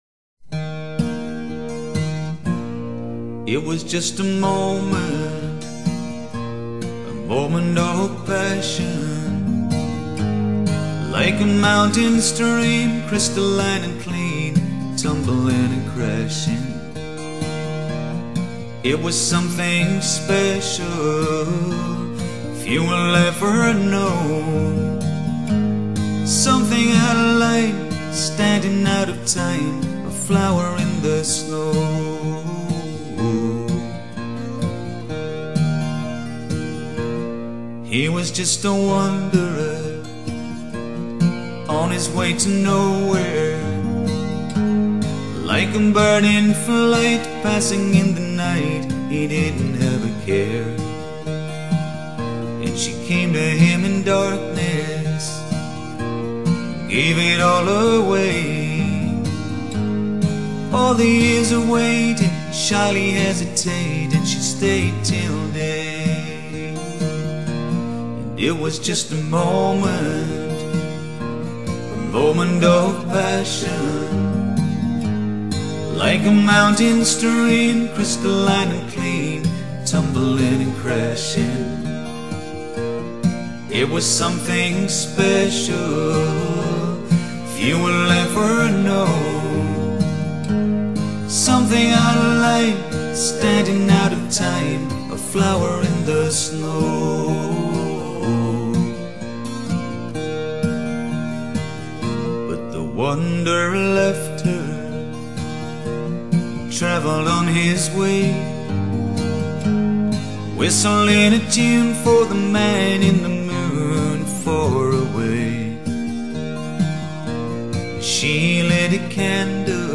吉它音色清脆圓潤，唱作俱佳。